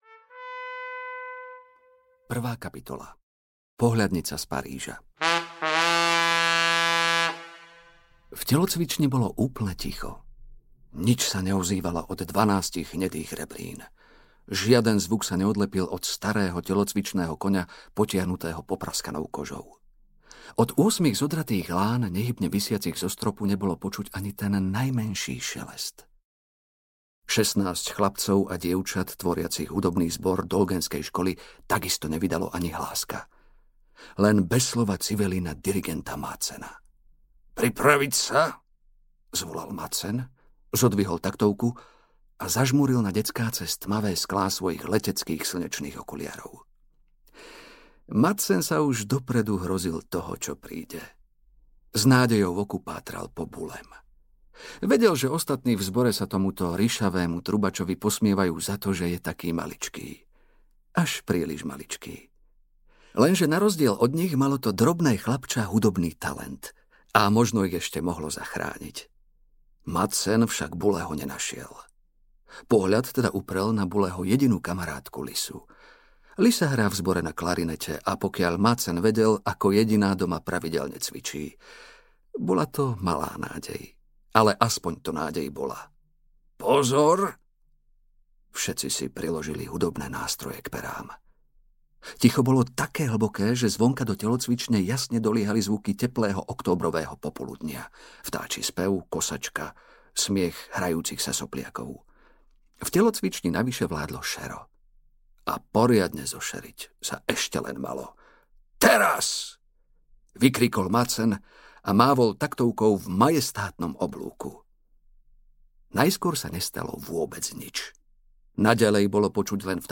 Časovaňa doktora Proktora audiokniha
Ukázka z knihy